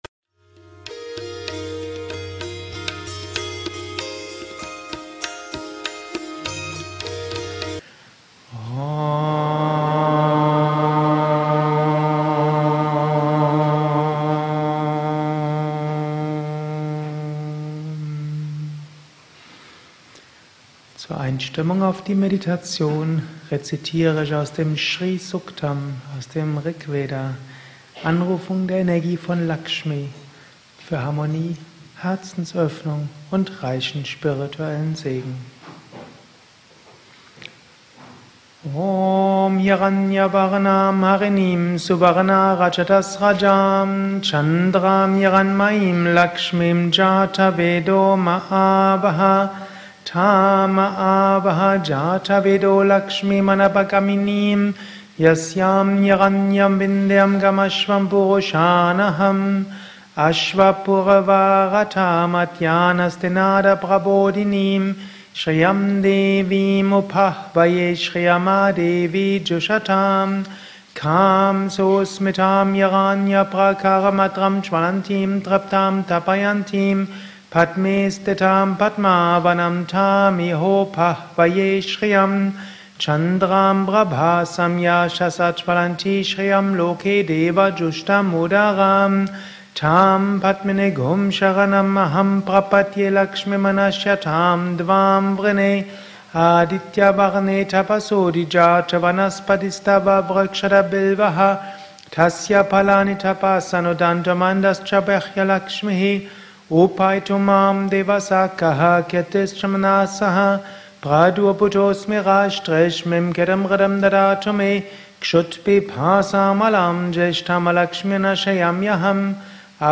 Mantra-Meditation Anleitung